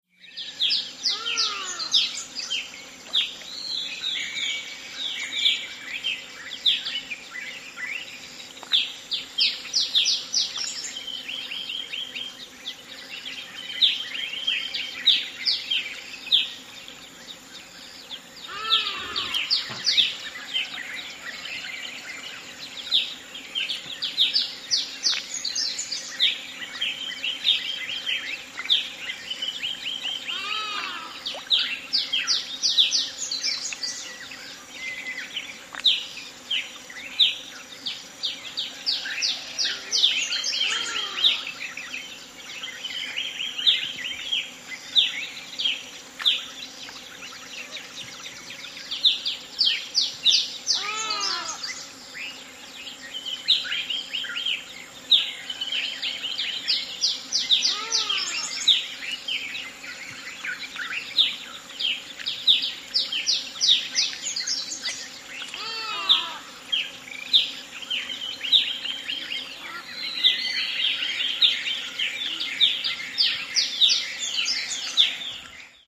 ANIMALS-WILD AFRICA: LION: Roaring with bird background, Seronera, Tanzania. Serengeti atmosphere at night.